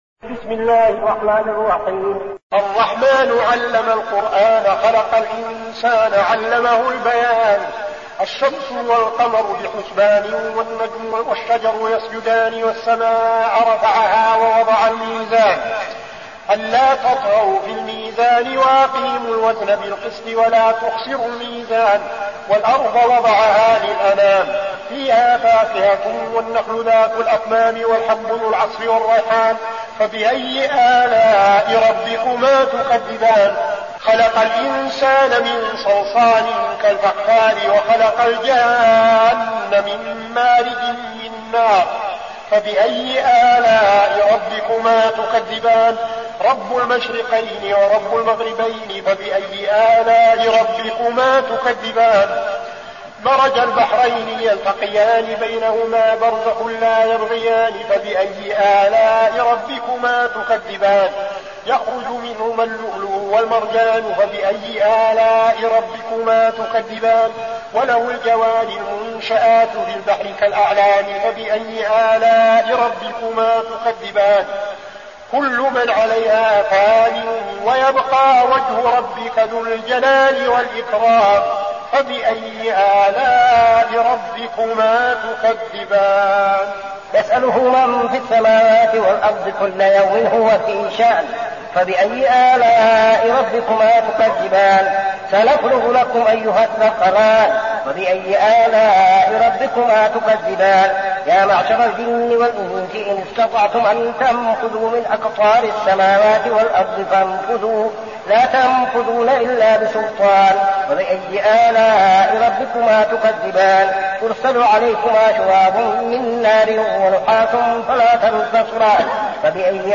المكان: المسجد النبوي الشيخ: فضيلة الشيخ عبدالعزيز بن صالح فضيلة الشيخ عبدالعزيز بن صالح الرحمن